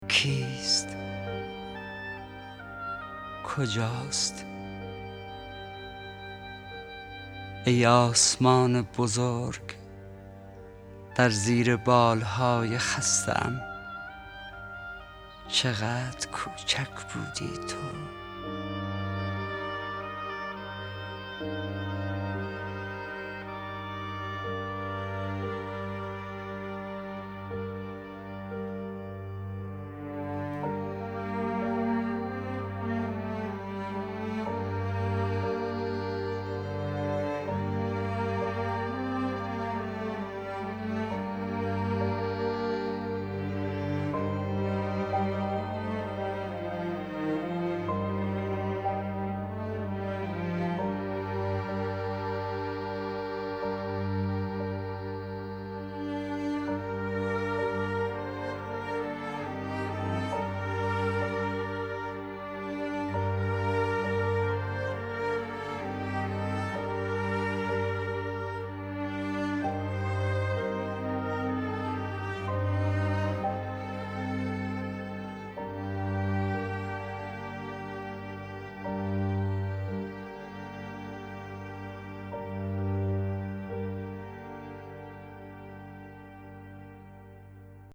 دانلود دکلمه کیست با صدای حسین پناهی
گوینده :   [حسین پناهی]